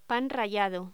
Locución: Pan rallado
voz